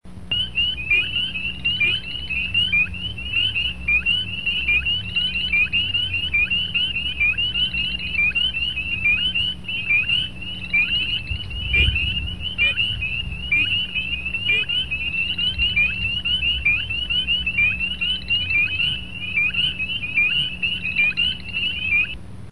call of the spring peepers drowned out everything else we tried to hear at night. They’d start just before sunset and go all night long.
Spring_Peeper.mp3